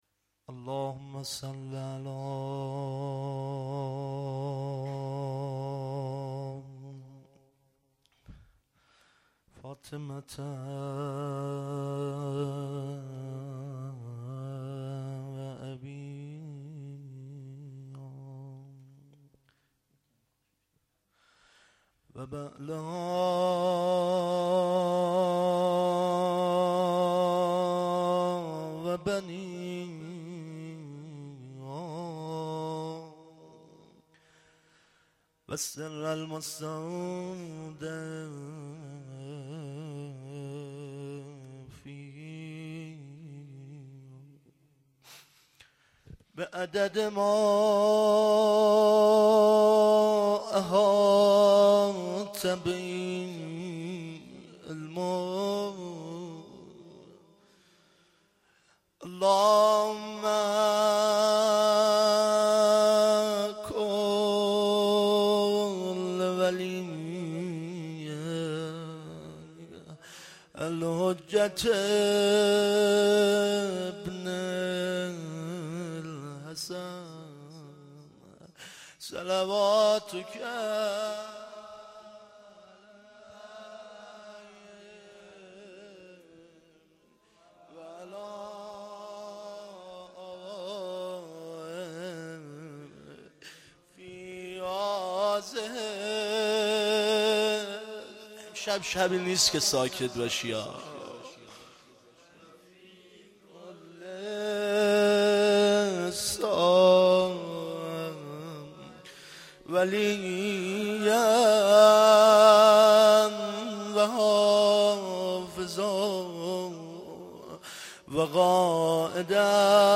roze.mp3